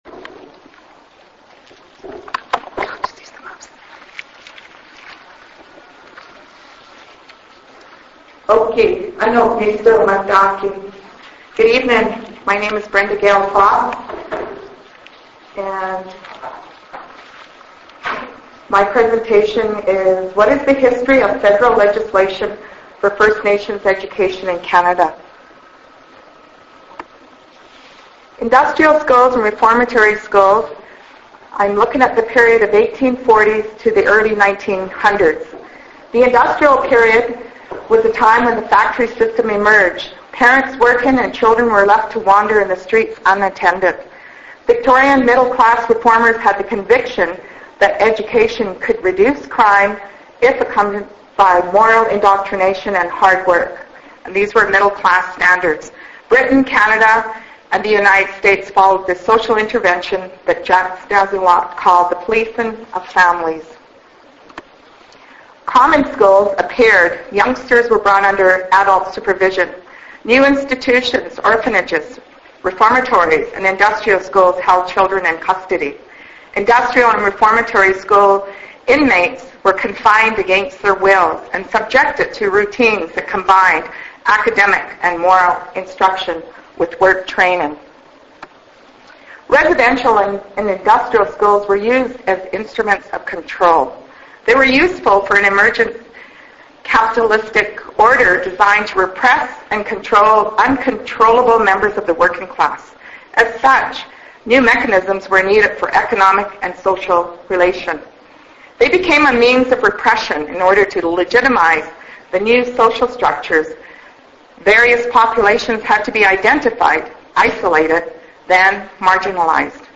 Venue: Lethbridge Public Library Theatre Gallery, Main Branch, 810 – 5th Ave. South Free admission, everyone welcome Many First Nations people are reacting with disappointment to the Federal Government’s proposed education legislation for First Nations that was released recently.